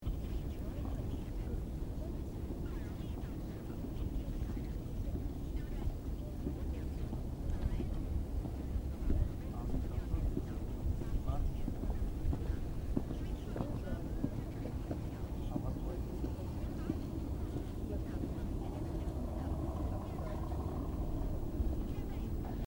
Сегодня наловил отвратительные наводки от двух радиовышек (Русско-Китайская граница) при попытке записать звук трения льда на реке.
Микрофоны(исправны), кабели(исправны, баланс), рекордер(исправен), кабель питания рекордера (два кабеля - холодный/горячий без экрана с крокодилами на конце- аккумуляторные клеммы естественно оголены).